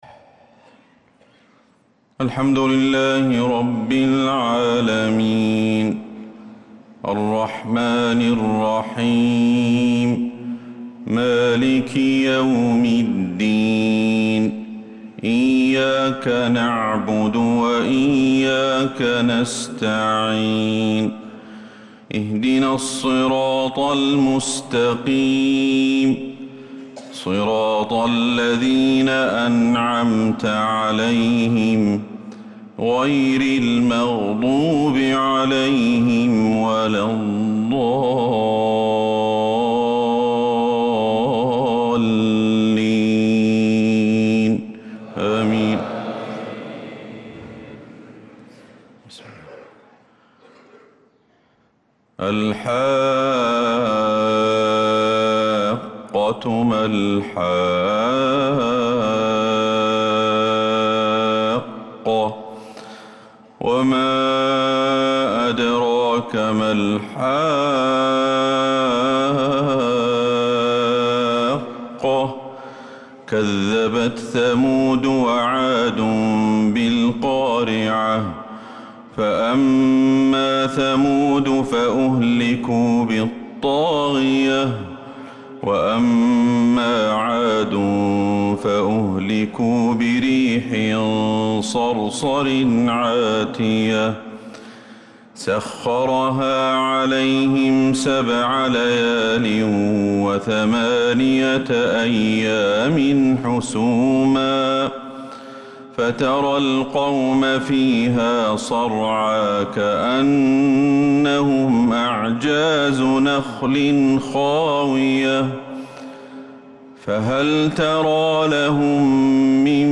صلاة الفجر للقارئ أحمد الحذيفي 6 شوال 1445 هـ